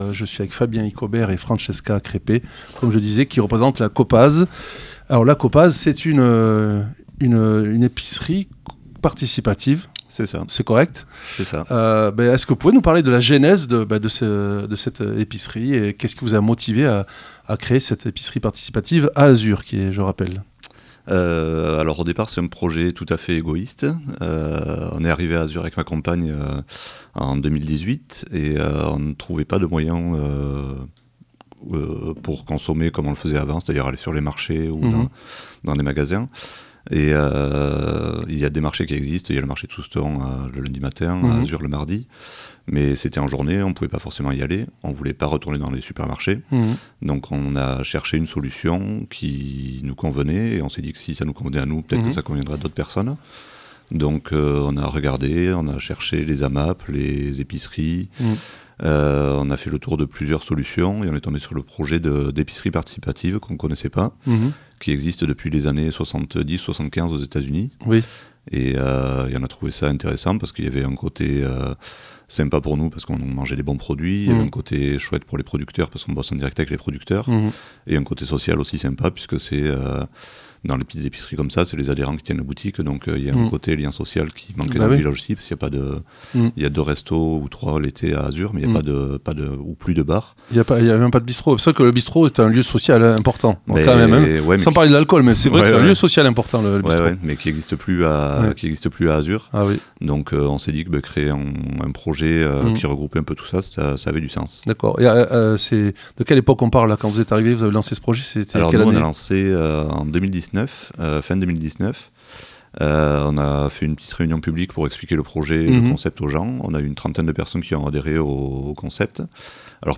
L'invité(e) du 12-13 de Soustons recevait aujourd'hui Coop'AZ, l'épicerie participative d'AZUR.